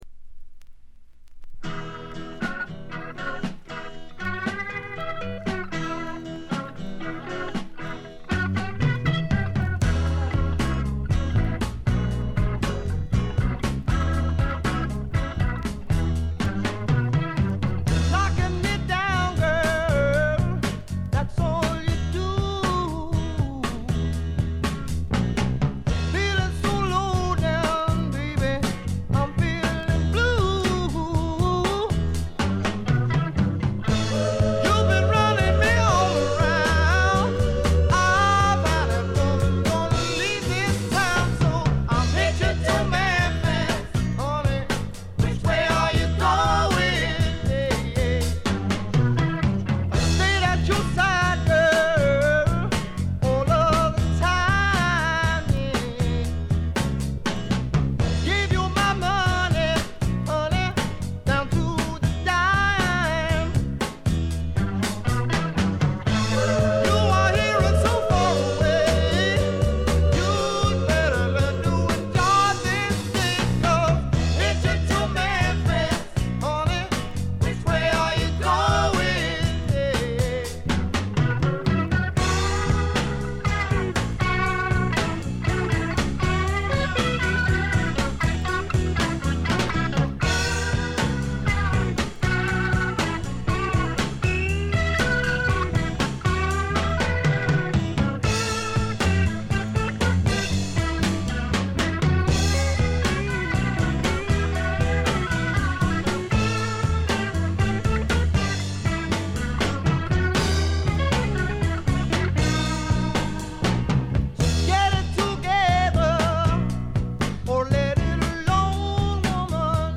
で内容はというとザ・バンドからの影響が色濃いスワンプ裏名盤であります。
試聴曲は現品からの取り込み音源です。